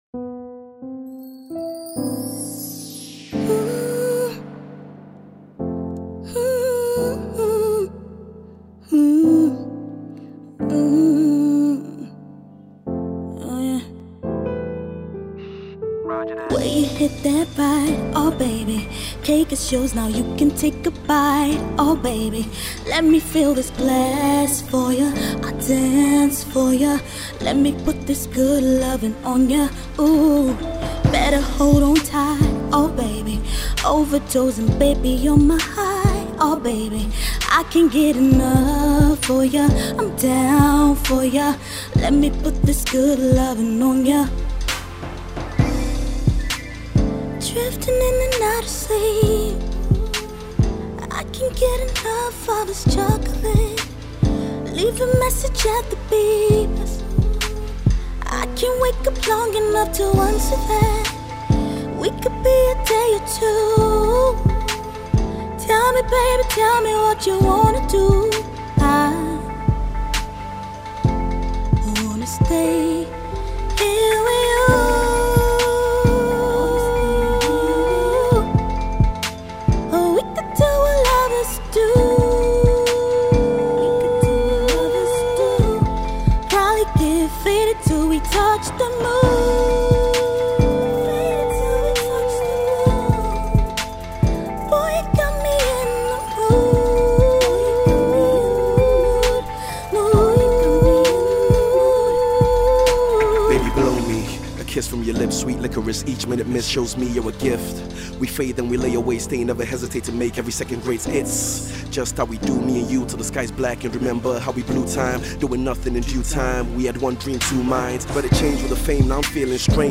songstress